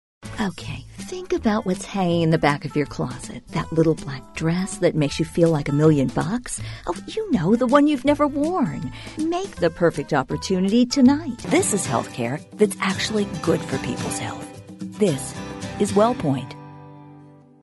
Ya sea que quieras ser cálido, amigable y conversador ... optimista y enérgico ... o un poco de autoridad y seriedad natural ... MÁS toneladas de personajes / acentos / edades, todos con verdad emocional